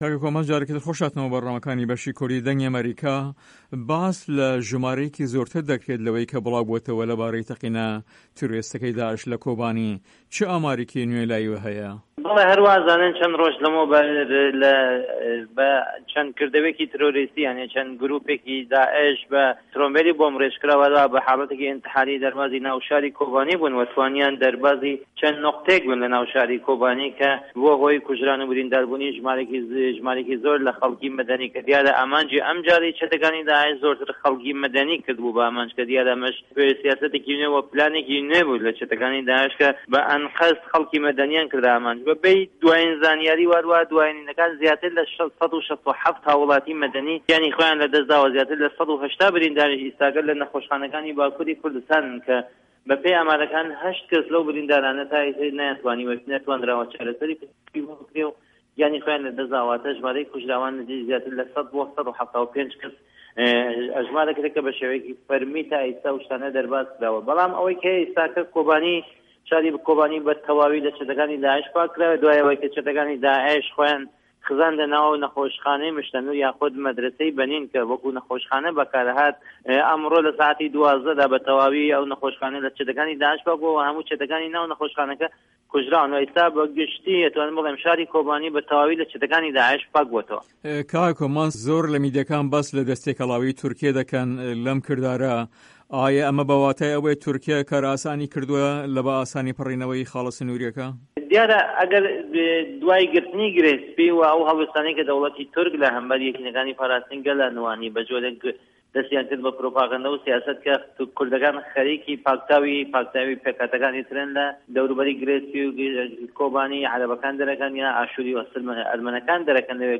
هه‌ڤپه‌یڤینێکدا